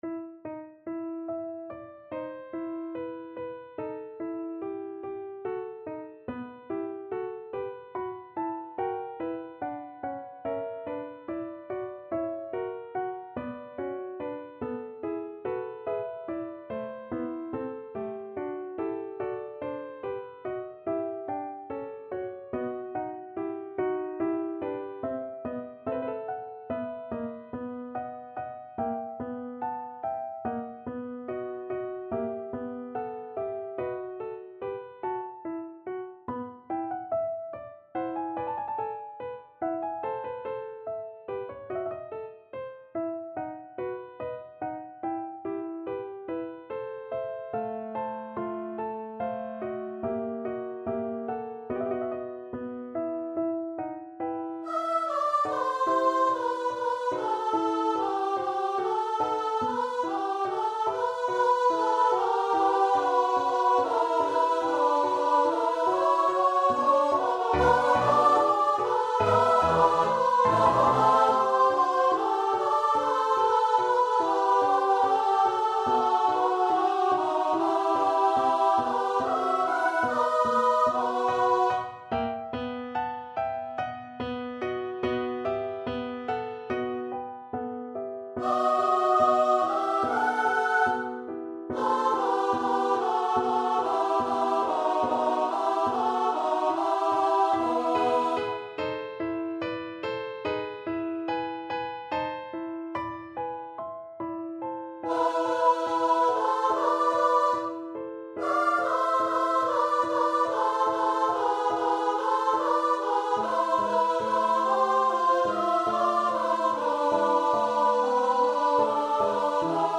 So ist mein Jesus; Sind Blitze, sind Donner (St. Matthew Passion) Choir version
Choir  (View more Intermediate Choir Music)
Classical (View more Classical Choir Music)